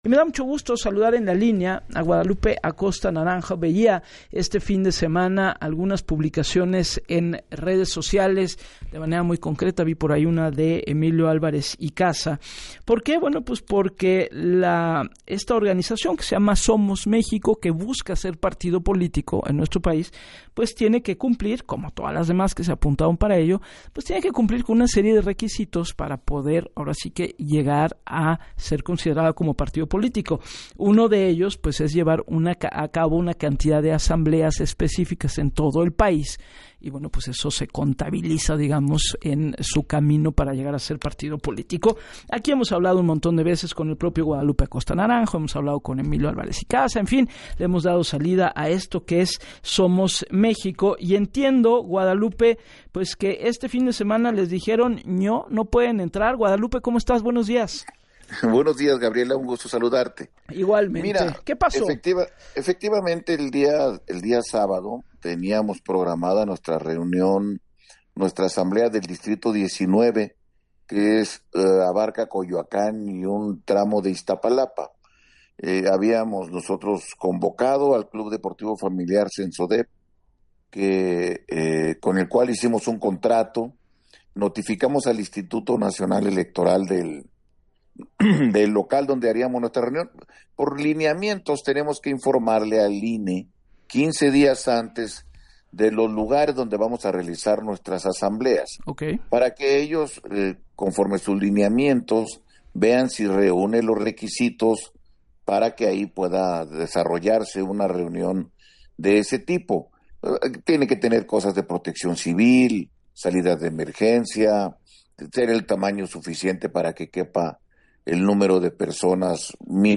En entrevista para “Así las Cosas” con Gabriela Warkentin, detalló que “Somos México” notificó al INE, que de acuerdo a los lineamientos debe estar enterado de las asambleas con 15 días de anticipación para que puedan revisar el lugar y verificar que “cumple con los requerimientos para las 300 o más personas”. y que efectivamente “el INE hizo la visita de rutina y autorizó la realización de la asamblea”.